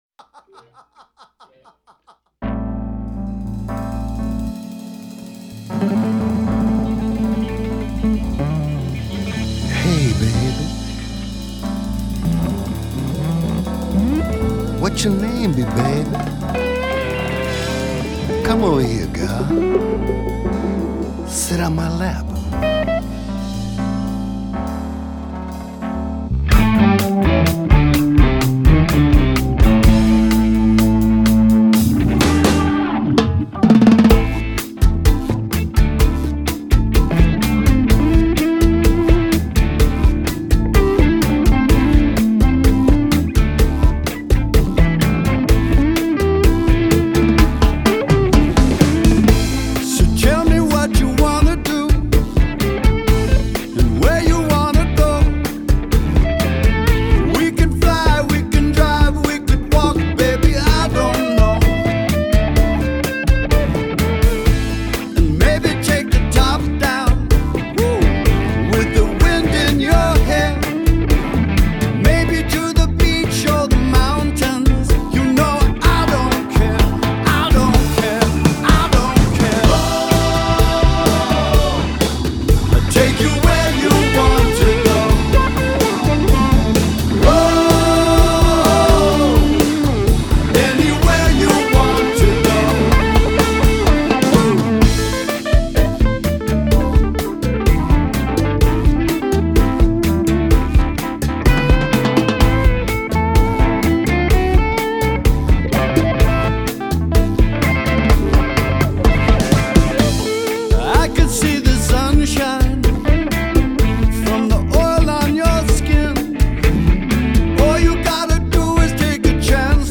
Genre: Classic Rock, Blues Rock